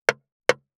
461,切る,包丁,厨房,台所,野菜切る,咀嚼音,ナイフ,調理音,まな板の上,料理,
効果音